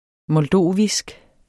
moldovisk adjektiv Bøjning -, -e Udtale [ mʌlˈdoˀvisg ] Betydninger fra Moldova; vedr. Moldova og moldoverne